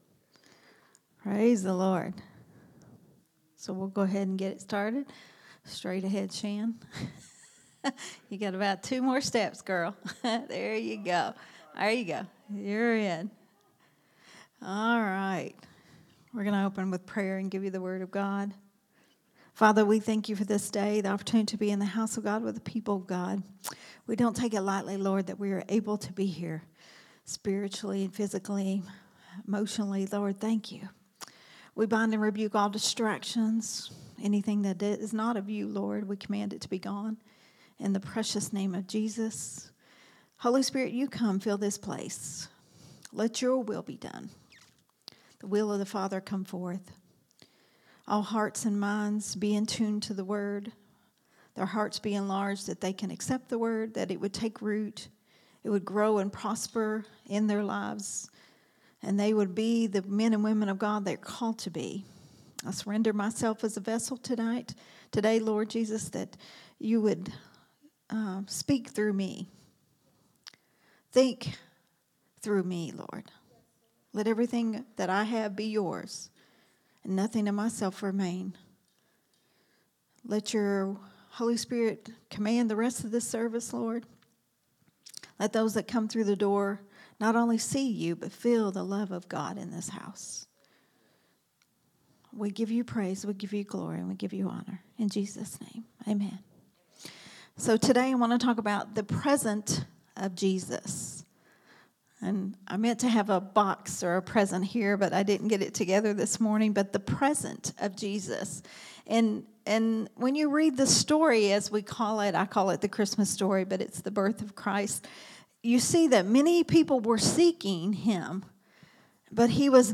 a Sunday Morning Risen Life teaching
recorded at Growth Temple Ministries on Sunday